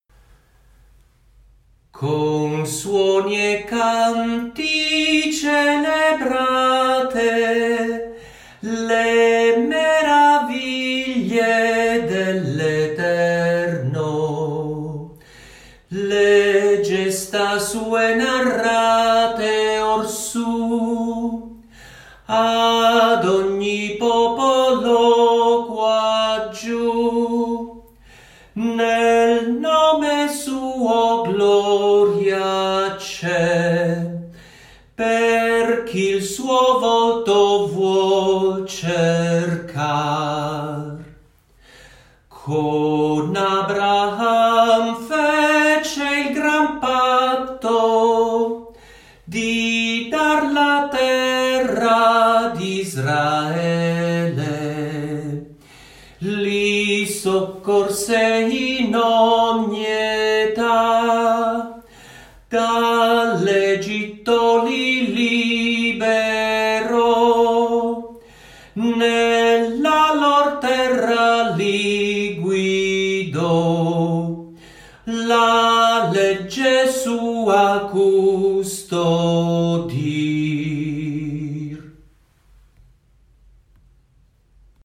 Salmi cantati
con accompagnamento della chitarra